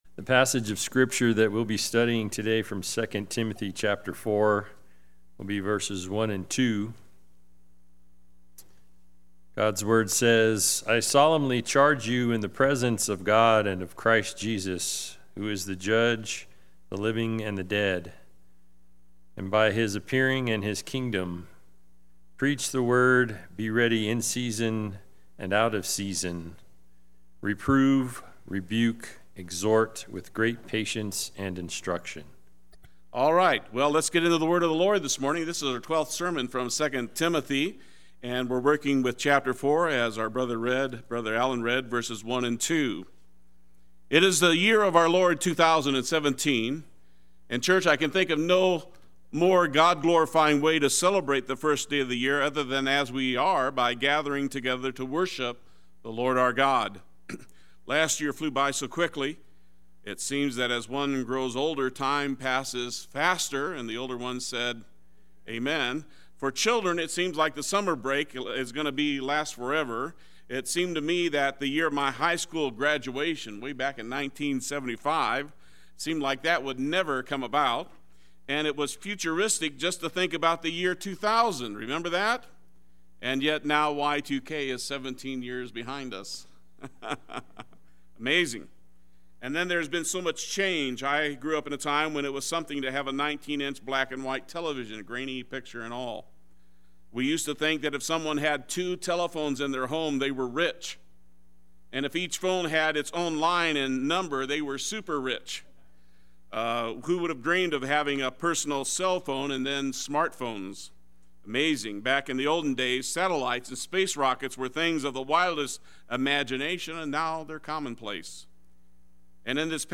Play Sermon Get HCF Teaching Automatically.
Preach the Word Sunday Worship